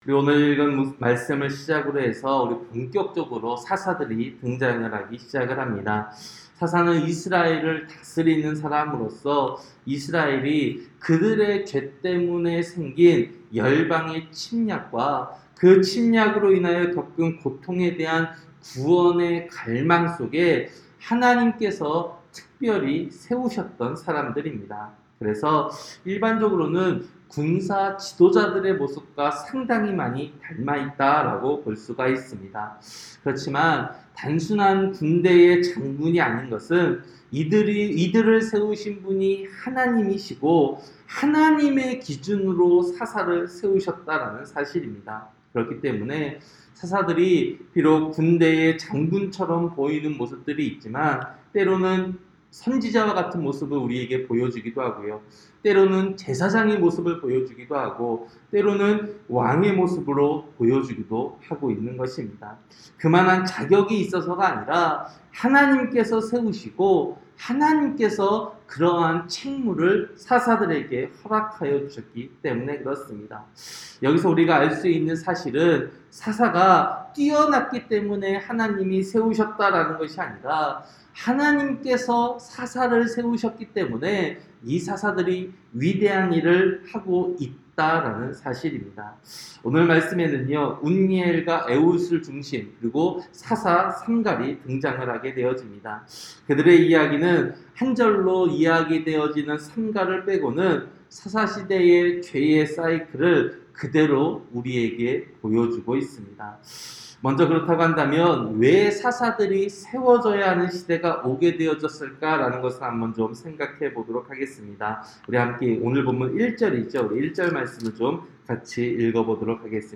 새벽설교-사사기 3장